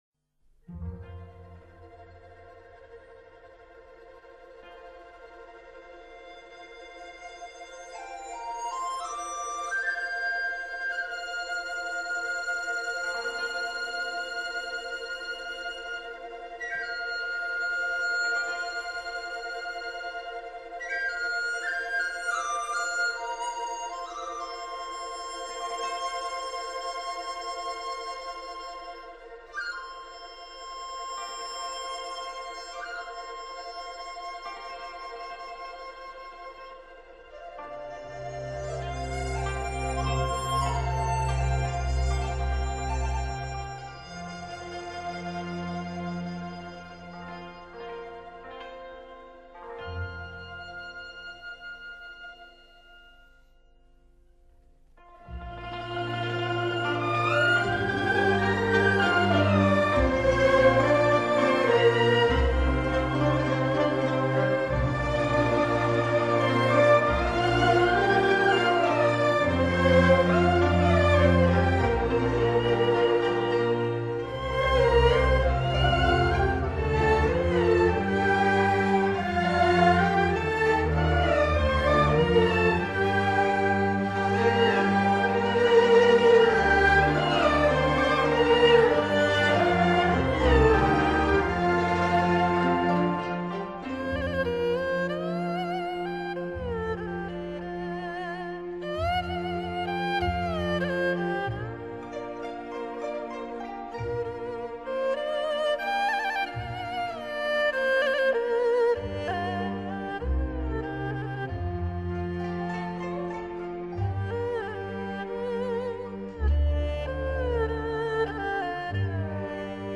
完全是采用了传统的民族器乐和演奏方法，既没有流行化，也没有西洋化